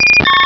Cri de Papilusion dans Pokémon Rubis et Saphir.